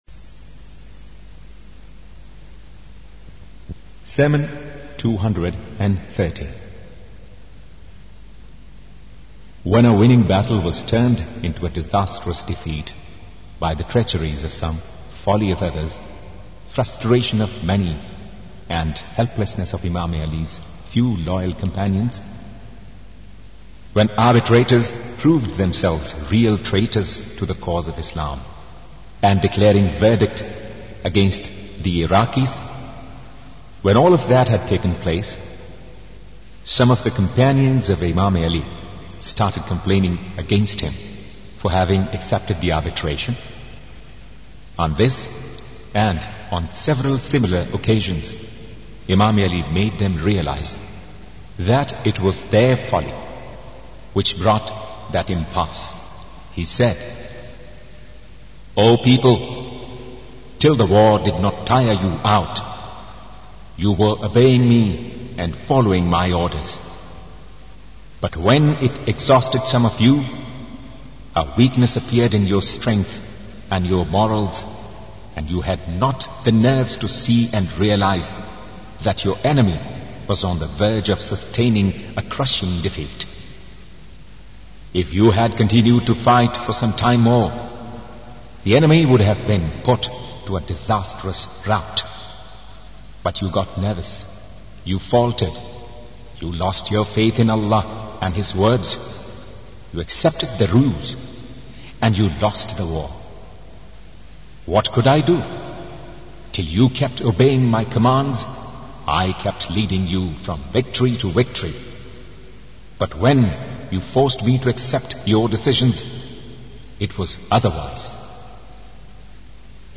sermons_213_to_227_a